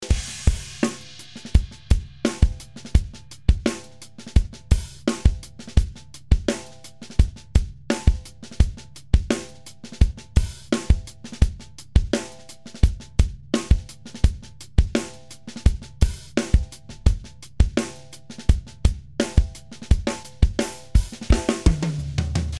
でもわかりやすい音色では、すっきりさせたり、より自然な雰囲気で穏やかにさせることができます。
かけているところとそうでないところのコントラストがわかりやすくなっていつつも、それ以外の音は破綻することなく元の雰囲気を保っていますね。